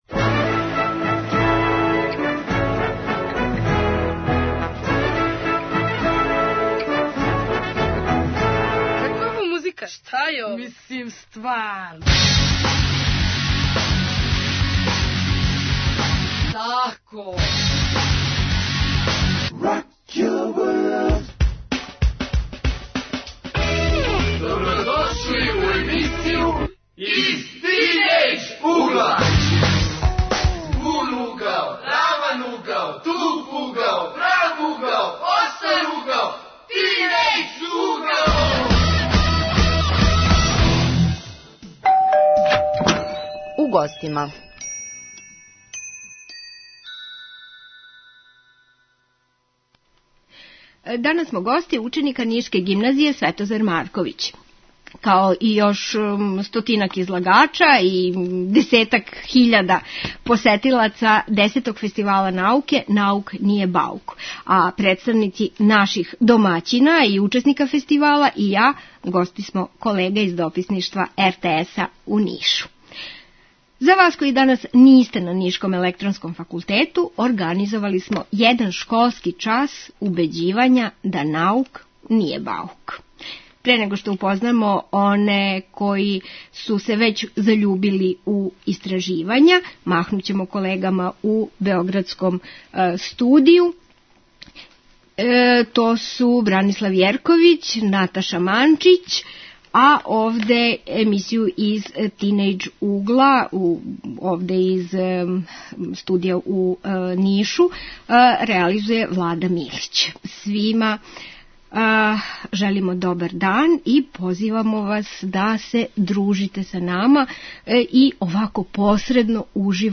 Емисије се емитује из студија Дописништва РТС-а у Нишу и посвећена је Десетом фестивалу науке: Наук није баук који организују ученици Гимназије 'Светозар Марковић'.